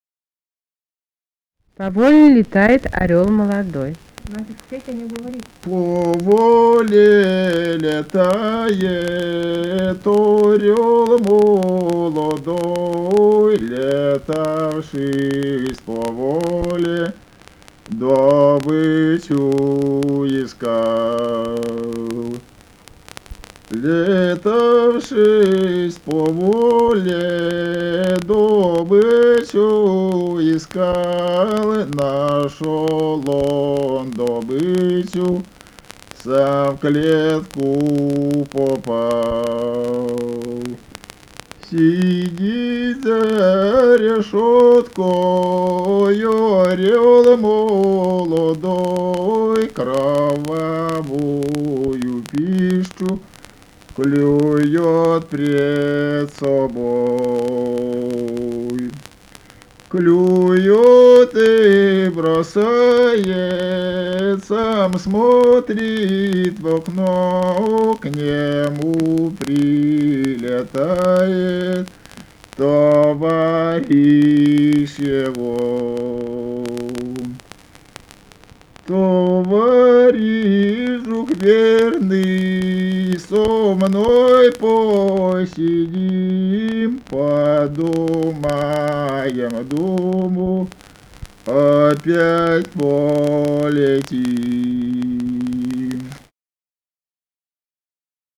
Этномузыкологические исследования и полевые материалы
Архангельская область, с. Койда Мезенского района, 1965, 1966 гг.